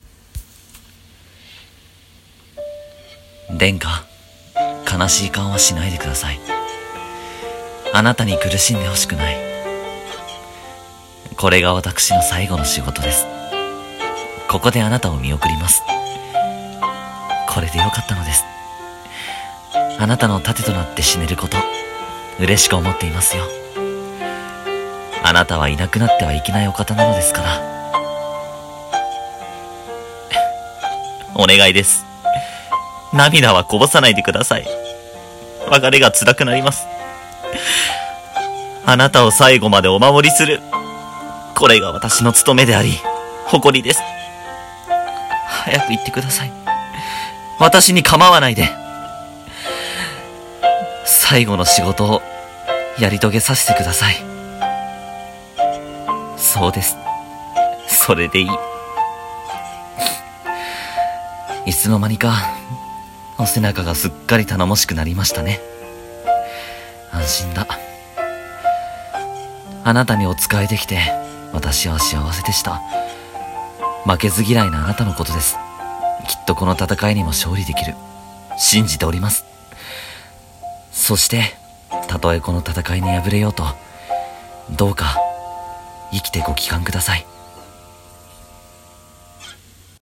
【一人声劇】「従者の最期」